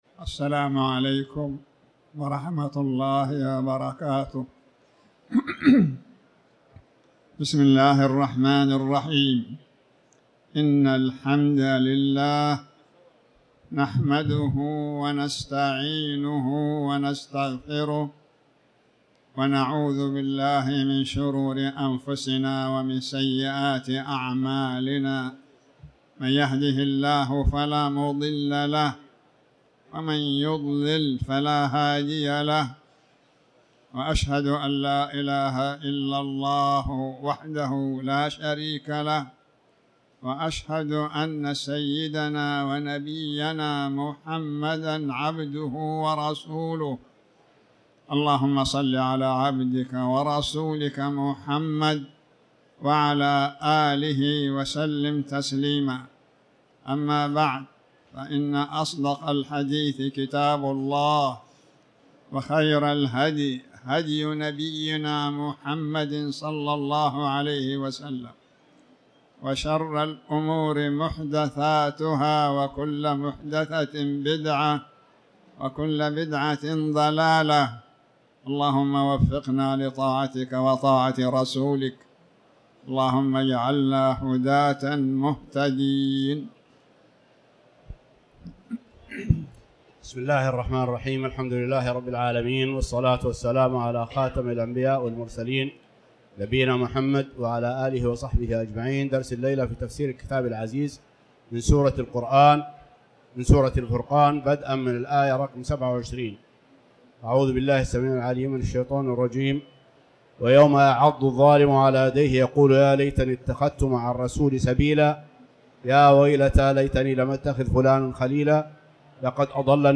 تاريخ النشر ١٣ رجب ١٤٤٠ هـ المكان: المسجد الحرام الشيخ